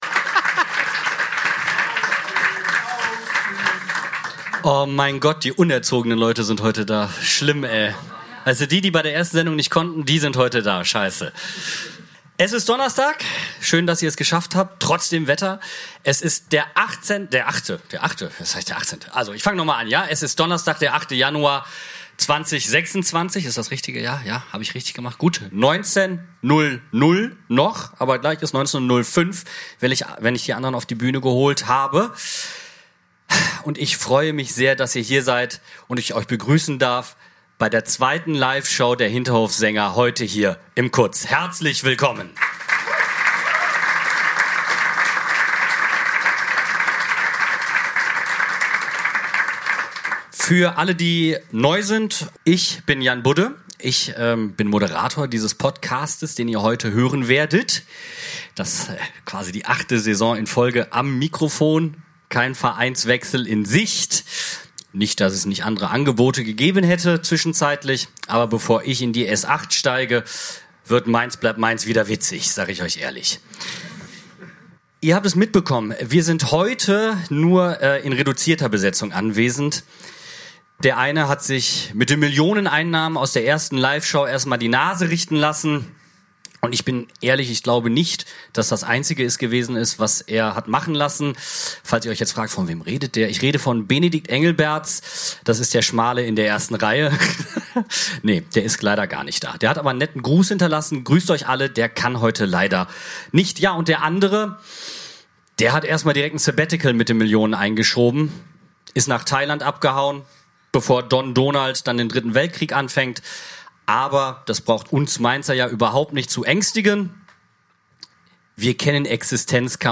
S08.E18 - Aufbruchsstimmung im Abtietzkampf (die zweite Live-Show im KUZ) ~ Hinterhofsänger-Talk Podcast